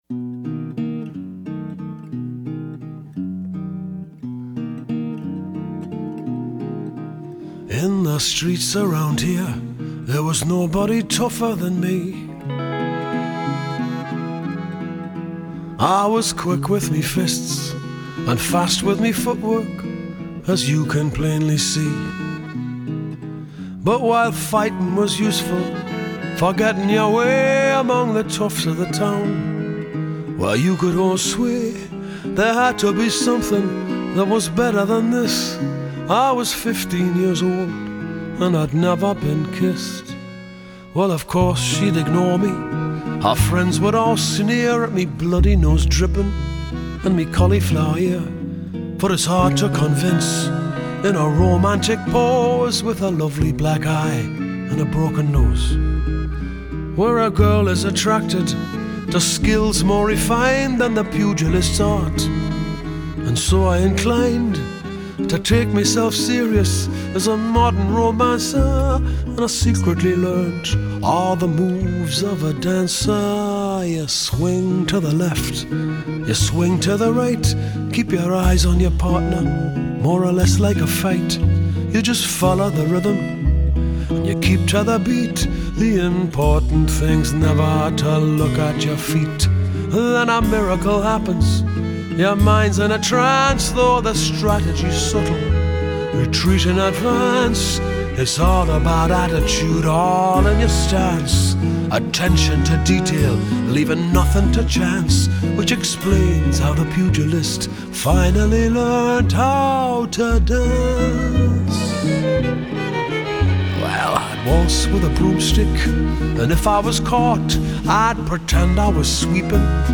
álbum postindustrial
delicadísima canción